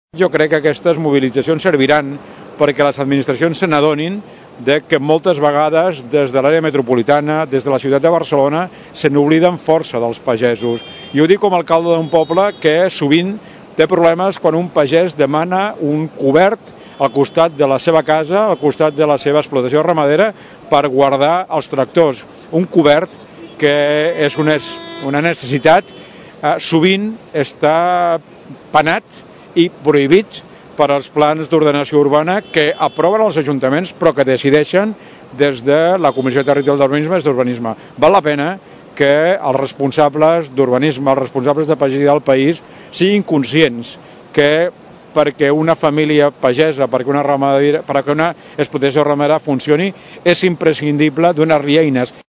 L’alcalde de Tordera, Joan Carles Garcia ha reivindicat el valor de la pagesia i del sector primari per un país. Garcia creu que les administracions s’obliden massa sovint del pagès i això també afecta als pagesos torderencs.